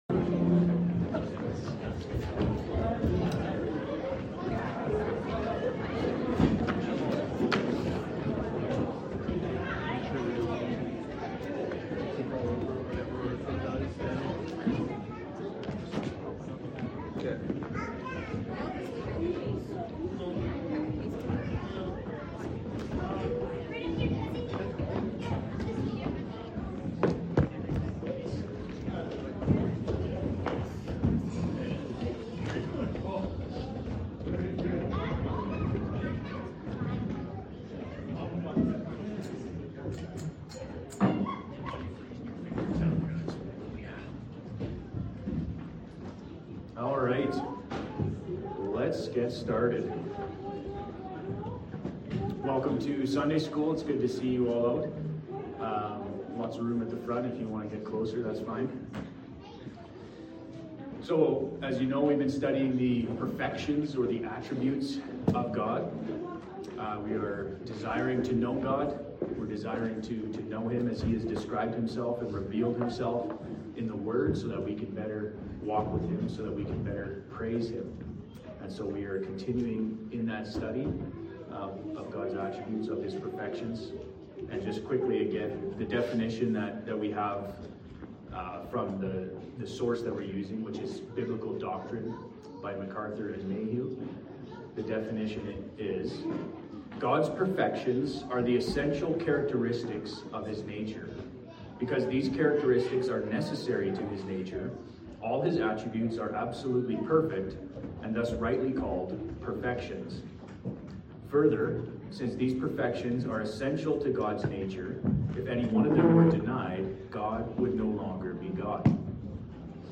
Category: Sunday School